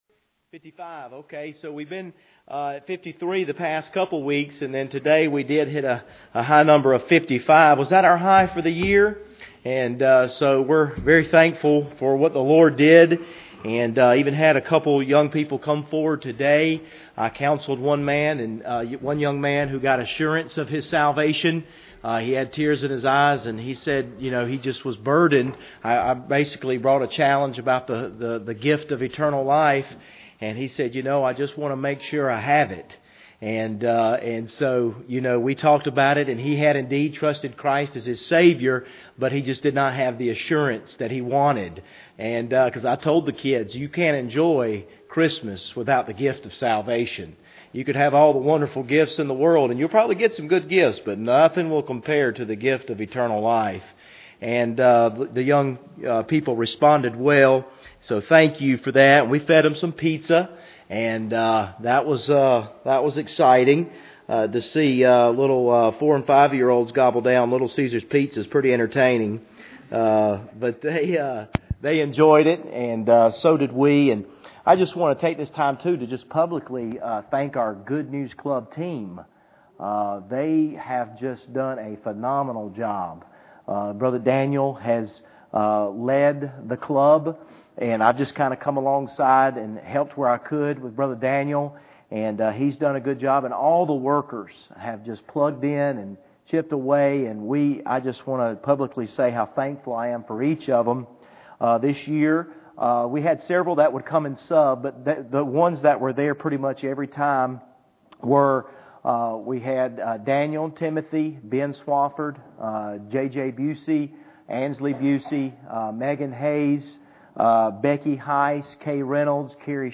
Passage: James 4:1-10 Service Type: Wednesday Evening